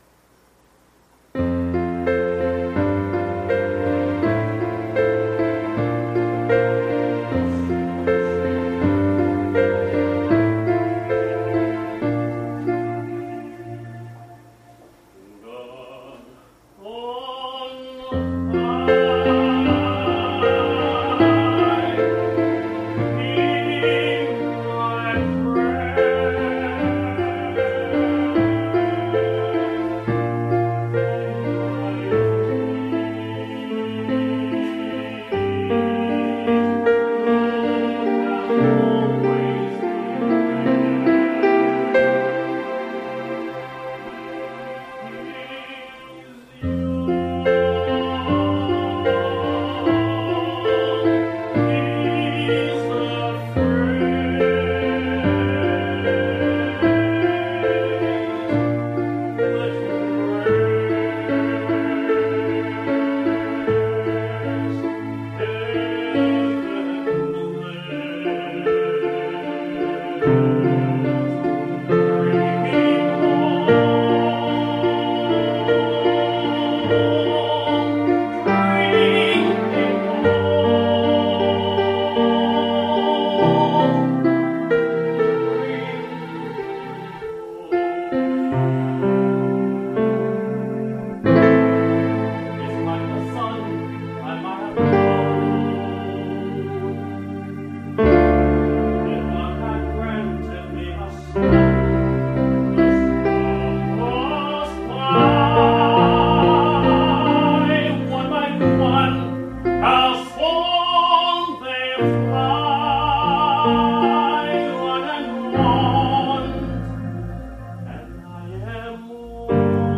특송